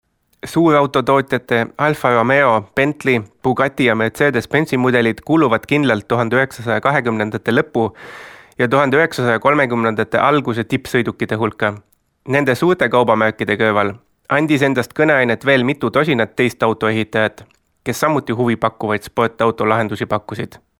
Estonian speaker, voice over, audio book narrator
Sprechprobe: eLearning (Muttersprache):